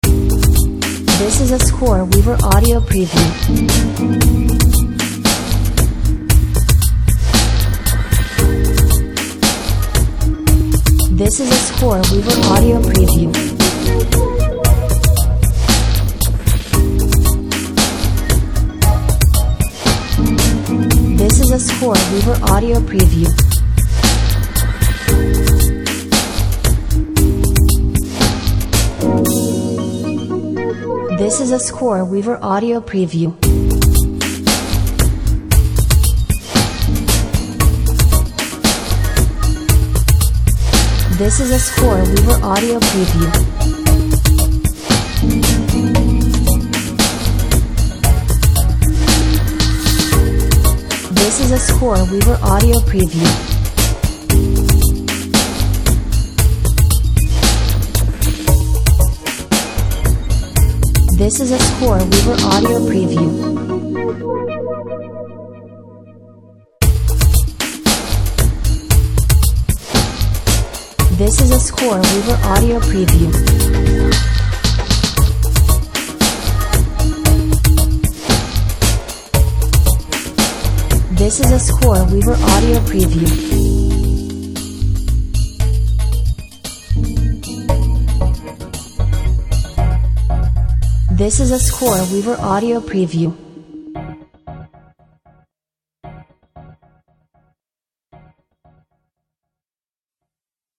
Sexy Lounge music for smokey night clubs.
Laid back downtempo electronica with lots of clever effects.
Overall sophisticated, hip and classy feeling.